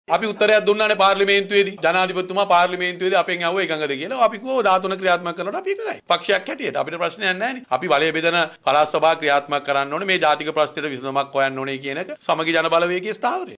මේ අතර සමගි ජනබලවේගය කැඳවා තිබූ මාධ්‍ය හමුවකදී 13 වන ආණ්ඩුක්‍රම ව්‍යවස්ථා සංශෝධනය පිළිබඳ මාධ්‍යවේදීන් කොළඹ නගරාධිපතිධූර අපේක්ෂක මුජිබර් රහුමාන් මහතාගෙන් විමසා සිටියා.